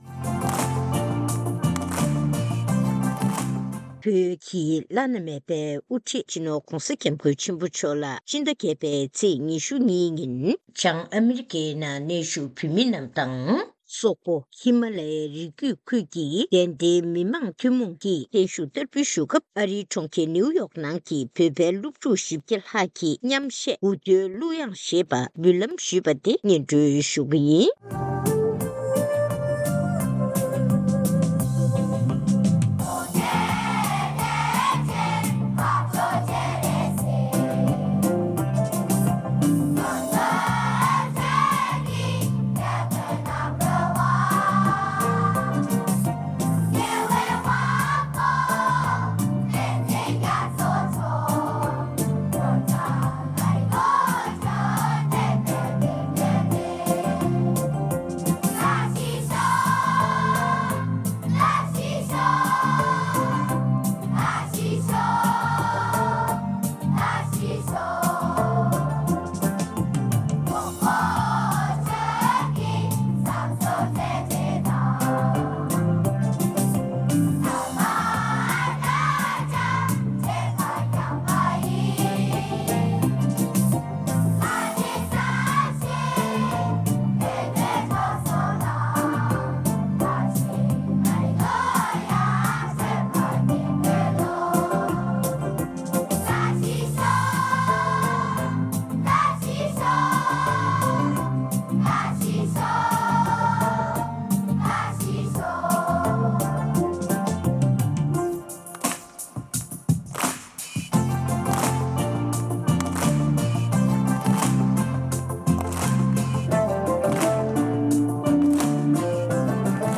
གནས་འདྲིའི་ལེ་ཚན